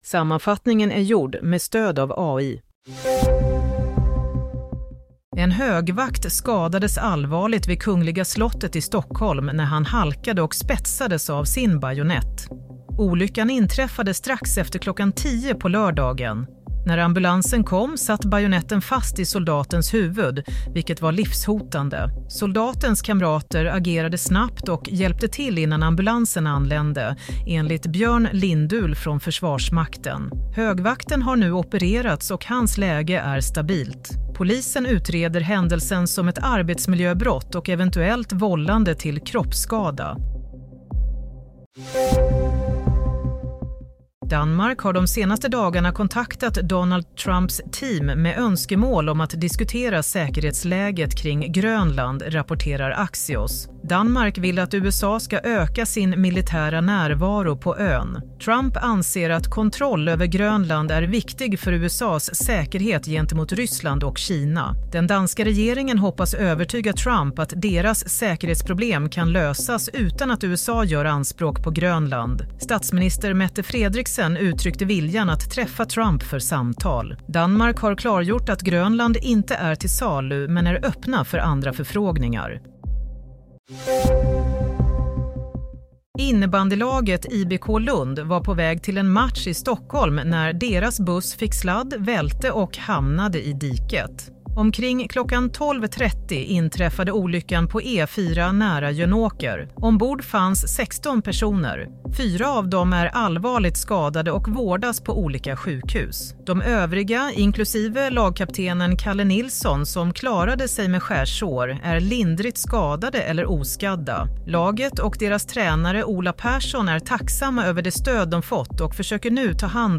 Nyhetssammanfattning – 11 januari 22:00
Sammanfattningen av följande nyheter är gjord med stöd av AI.